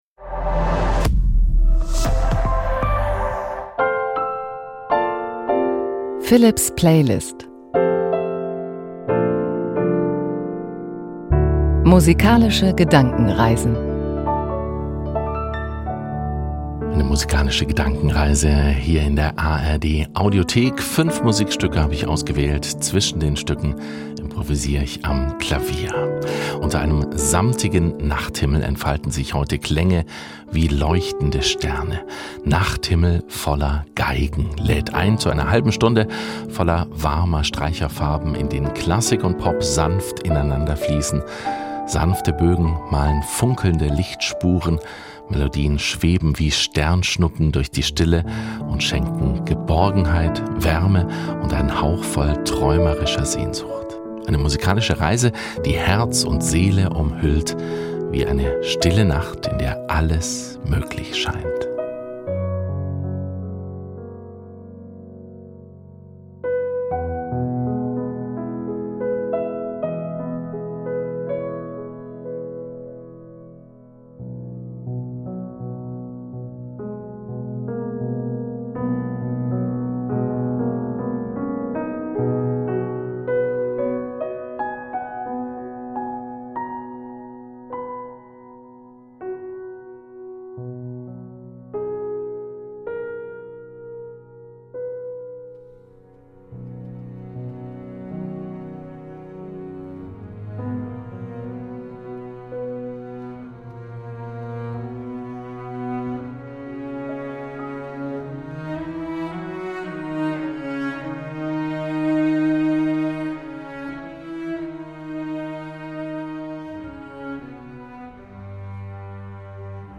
Klänge wie leuchtende Sterne: Warme Streicher-Farben aus Pop und Klassik schenken Dir Geborgenheit und Träume.